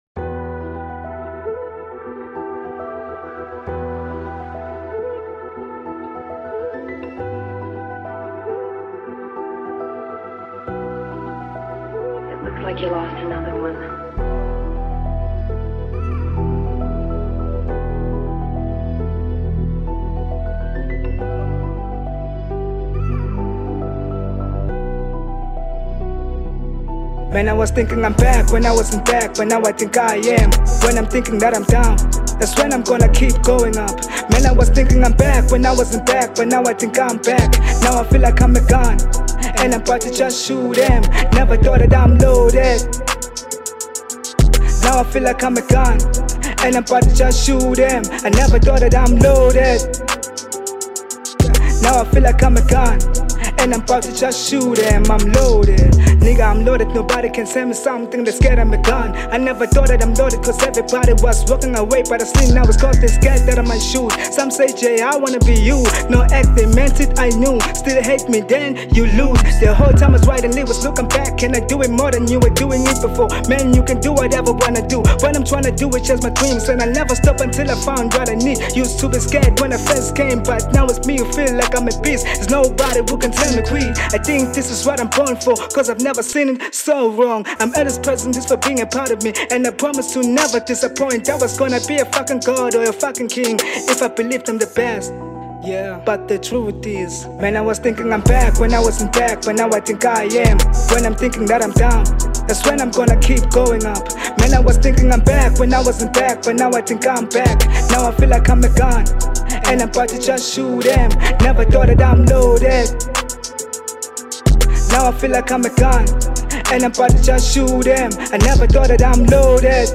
03:44 Genre : Hip Hop Size